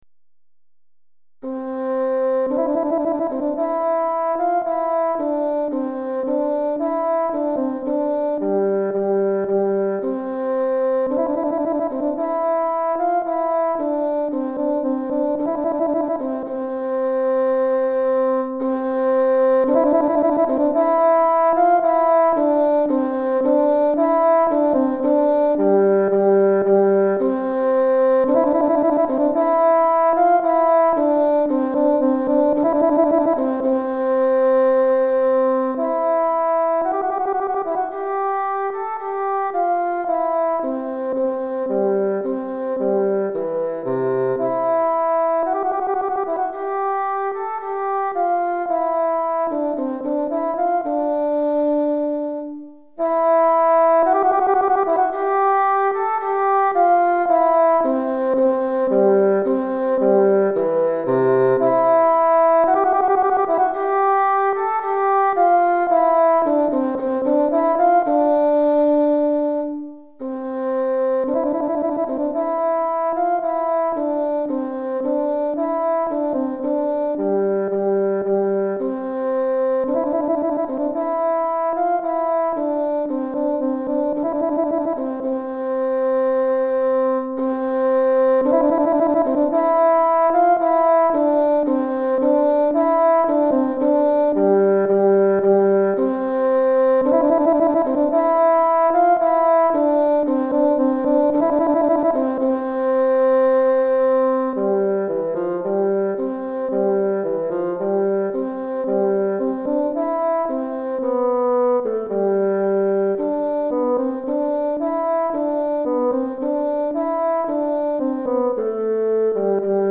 Basson Solo